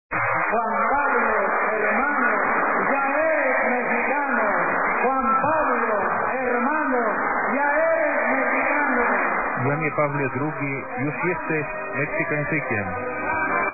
Na lotnisku odbyła się ceremonia pożegnalna.
Tamże żegnał Ojca Świętego nie tylko tłum ludzi...